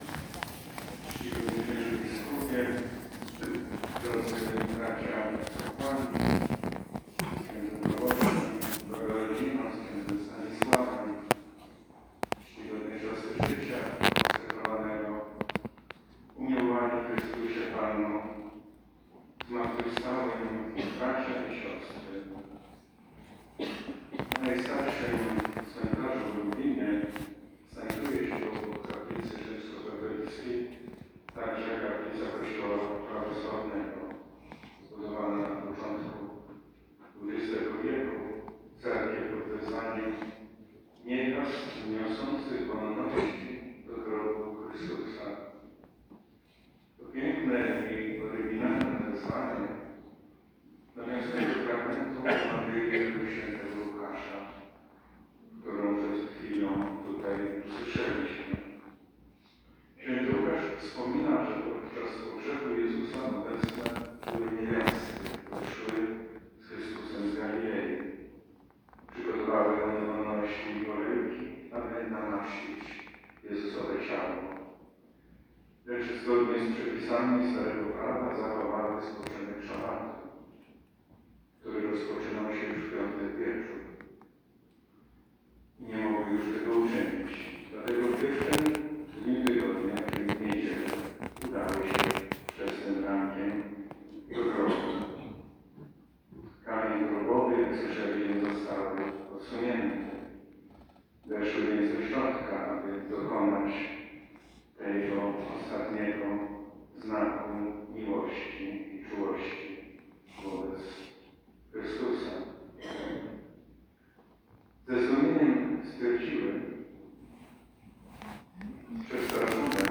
kazanie Arcybiskupa Stanisława Budzika
bp.Budzik.mp3